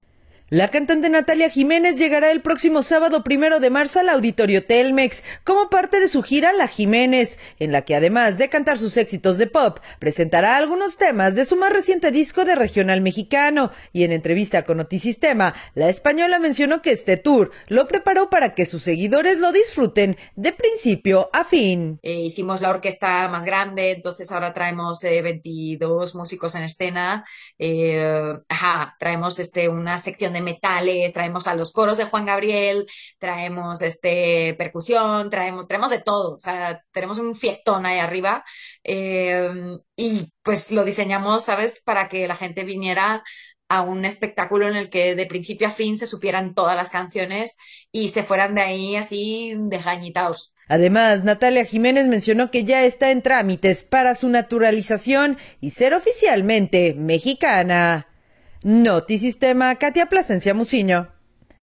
audio La cantante Natalia Jiménez llegará el próximo sábado 1 de marzo al Auditorio Telmex como parte de su gira “La Jiménez”, en la que además de cantar sus éxitos de pop, presentará algunos temas de su más reciente disco de regional mexicano y en entrevista con Notisistema, la española mencionó que este tour lo preparó para que sus seguidores lo disfruten de principio a fin.